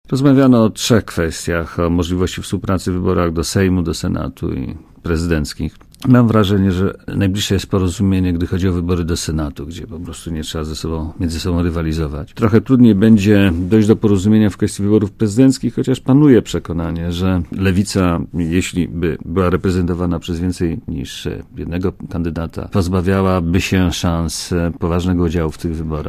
Mówi Włodzimierz Cimoszewicz Oceń jakość naszego artykułu: Twoja opinia pozwala nam tworzyć lepsze treści.